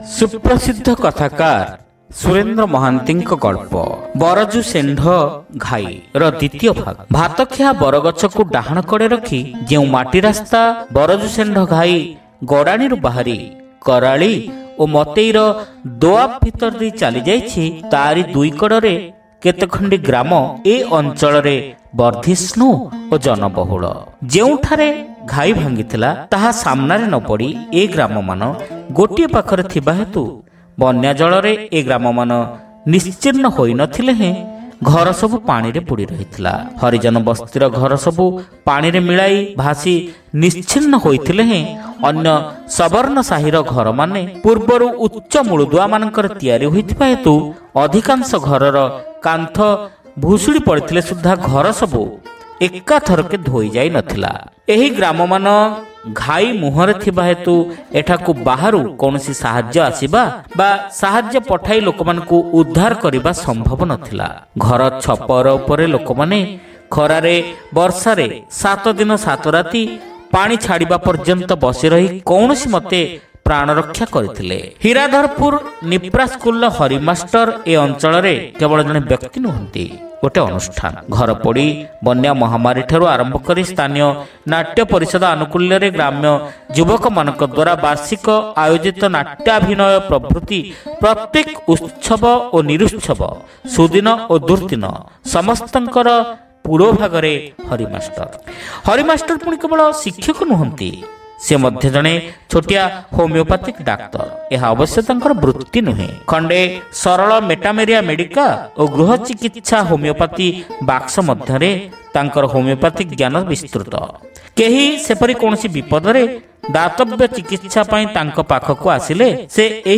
ଶ୍ରାବ୍ୟ ଗଳ୍ପ : ବରଜୁ ସେଣ୍ଢ ଘାଇ (ଦ୍ୱିତୀୟ ଭାଗ)